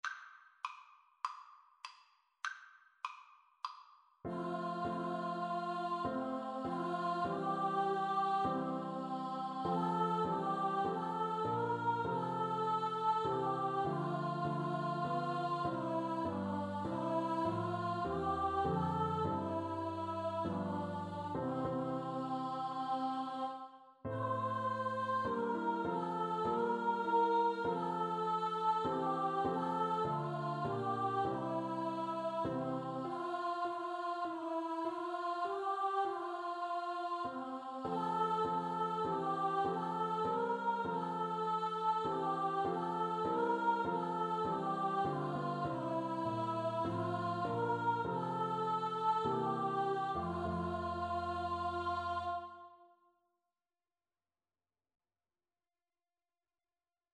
F major (Sounding Pitch) (View more F major Music for Choir )
4/4 (View more 4/4 Music)
Traditional (View more Traditional Choir Music)
o_come_PNO_kar1.mp3